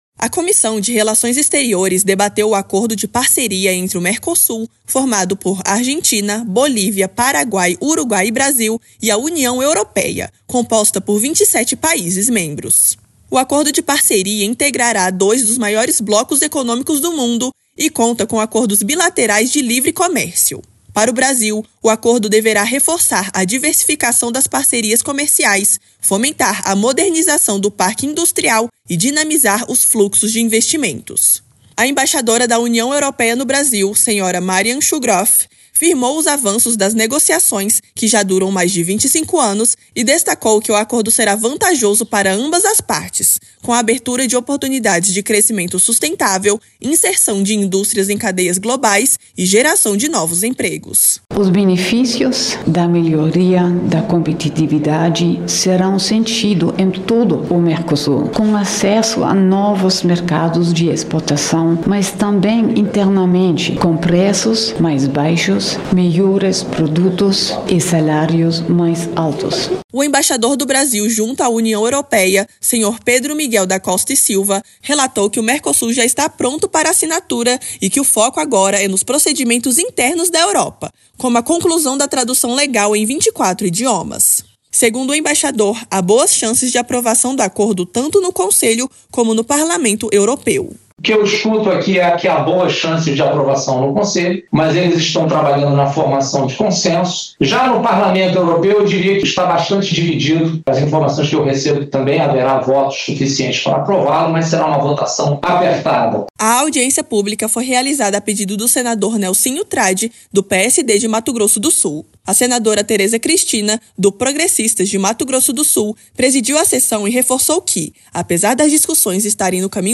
A embaixadora da União Europeia (UE) no Brasil, Marian Schuegraf, o embaixador do Brasil junto ao bloco europeu, Pedro Miguel da Costa e Silva, e a embaixadora da Dinamarca, Eva Bisgaard Pedersen, ressaltaram os progressos nas negociações do acordo Mercosul–União Europeia durante audiência pública na Comissão de Relações Exteriores (CRE) do Senado.